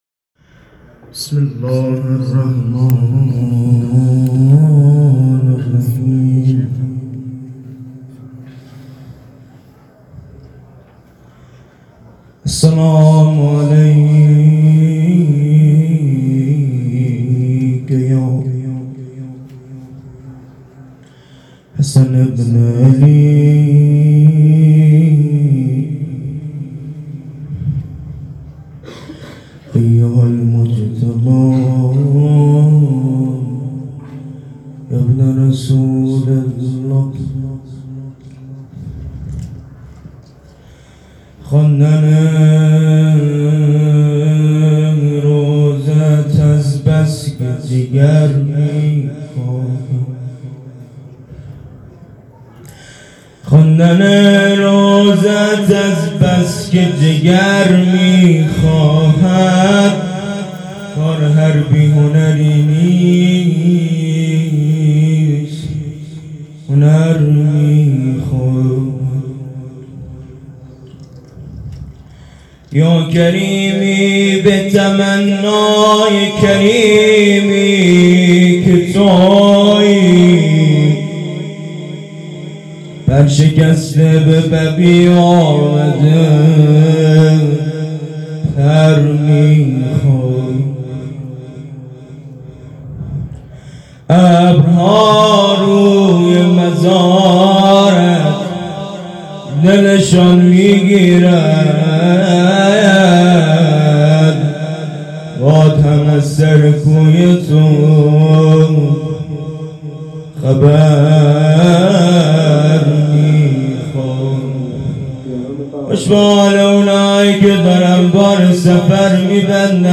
خیمه گاه - کمیت 135 - روضه ی شهادت امام حسن 98